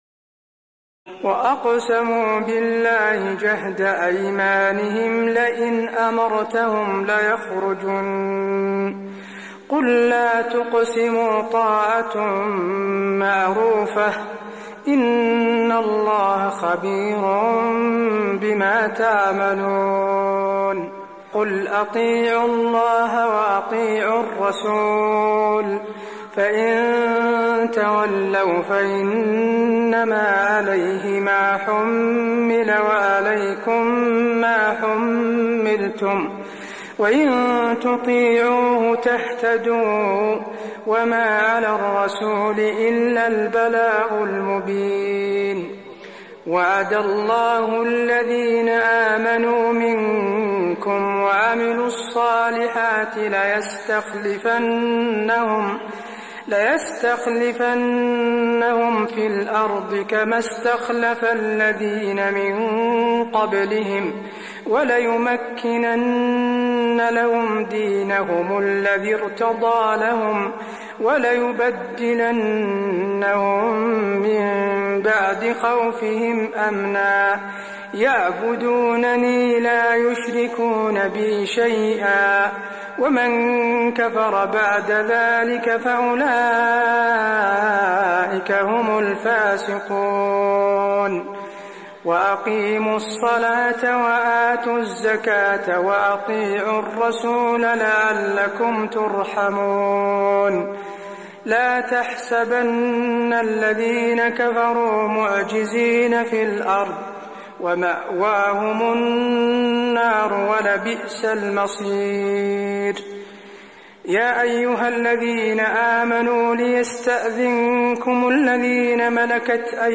تراويح الليلة السابعة عشر رمضان 1423هـ من سورتي النور (53-64) و الفرقان (1-20) Taraweeh 17 st night Ramadan 1423H from Surah An-Noor and Al-Furqaan > تراويح الحرم النبوي عام 1423 🕌 > التراويح - تلاوات الحرمين